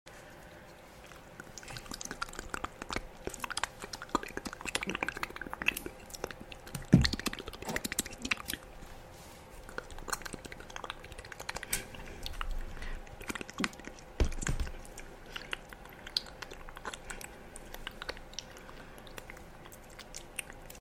Hair Clips + Mouth Sounds Sound Effects Free Download
hair clips + mouth sounds